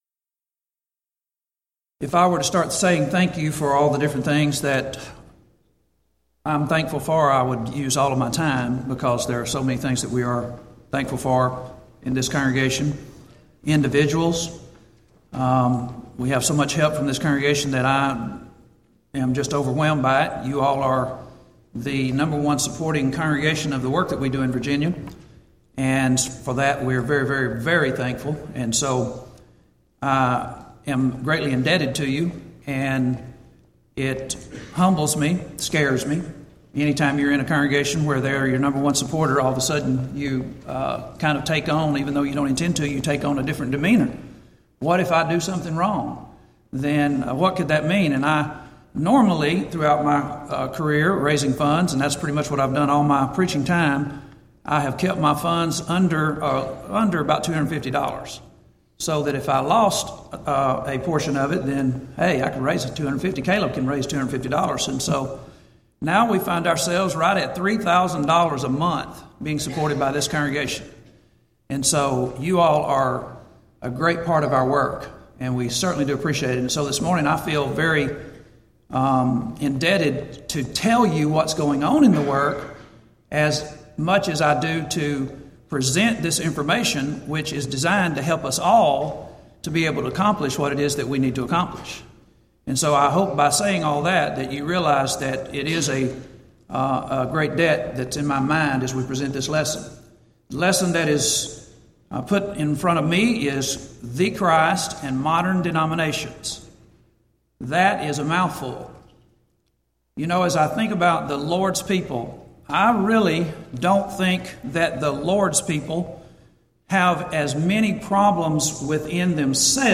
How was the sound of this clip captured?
Event: 21st Annual Gulf Coast Lectures